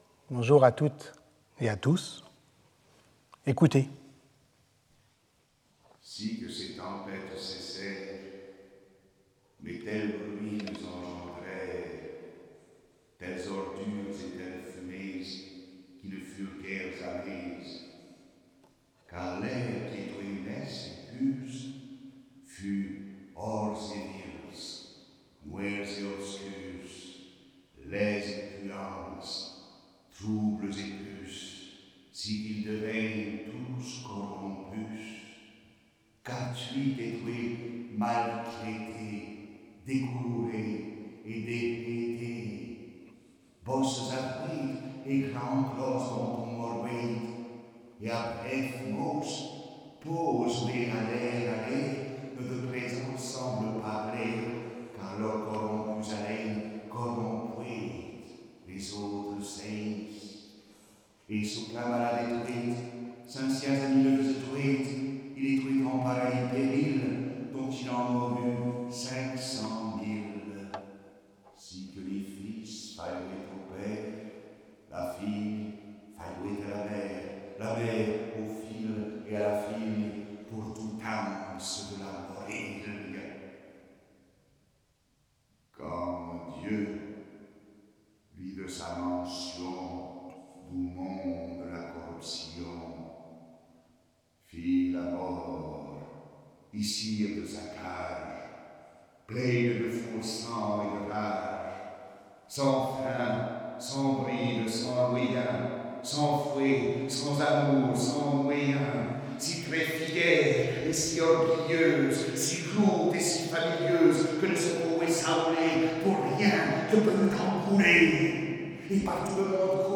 Sauter le player vidéo Youtube Écouter l'audio Télécharger l'audio Lecture audio À partir de l’analyse croisée du Jugement du roi de Navarre de Guillaume de Machaut, du Decameron de Boccace et de la correspondance de Pétrarque, cette dernière leçon tente de rassembler les acquis du cours autour de la question de l’agencement du temps.